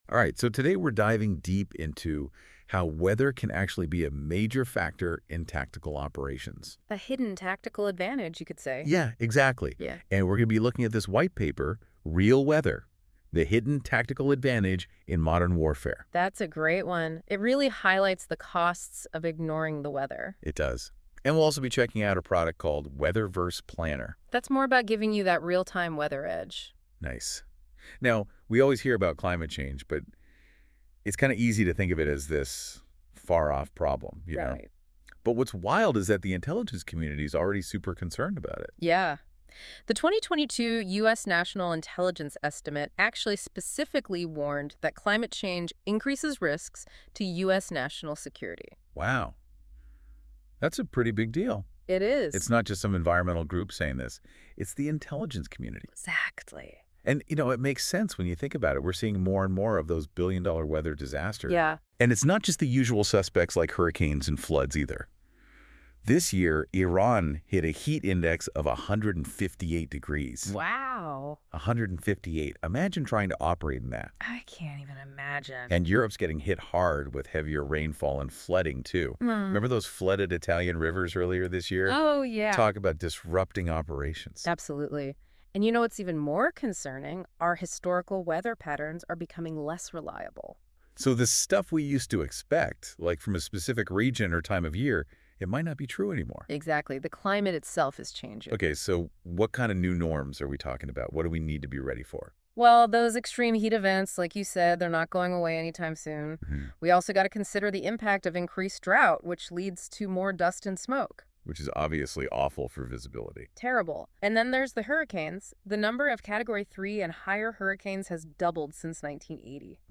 This summarized audio version of the white paper was AI generated.